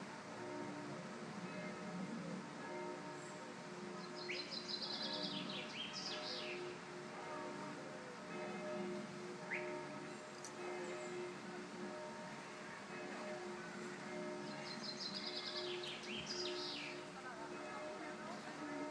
Castle sounds.